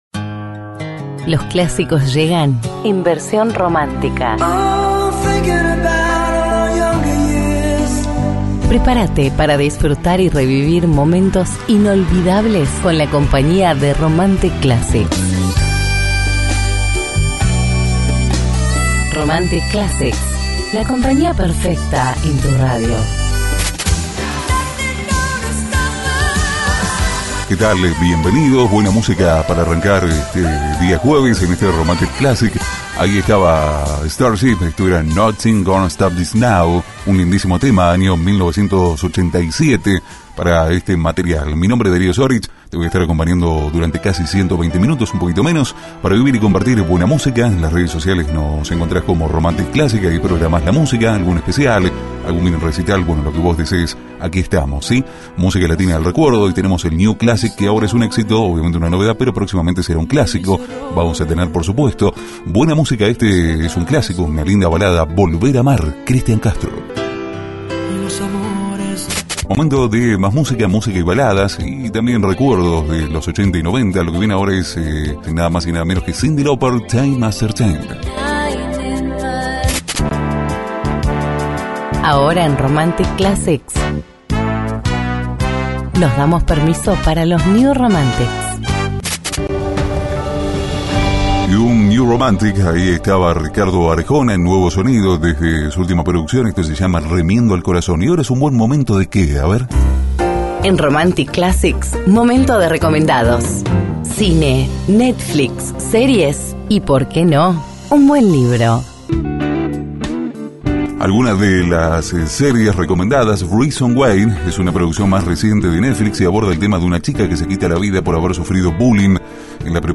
• Lentos y Baladas en Inglés y Castellano.
• Conducción: Masculina.